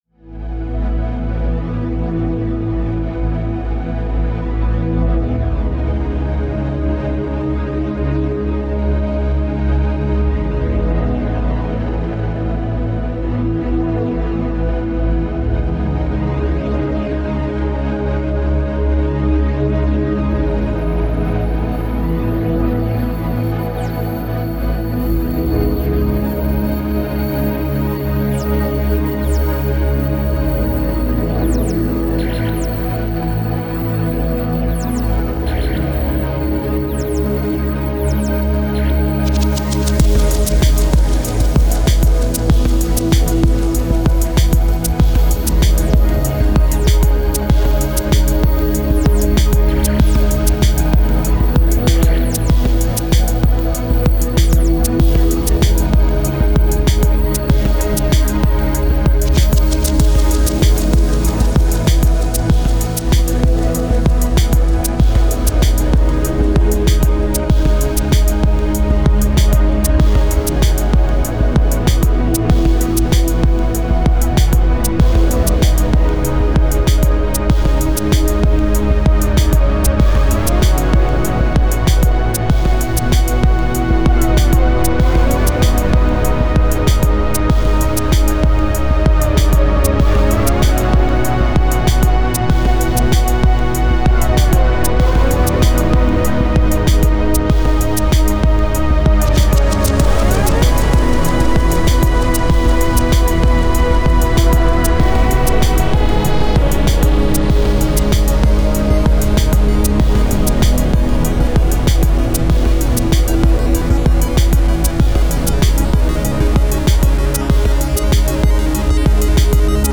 Genre: IDM, Ambient.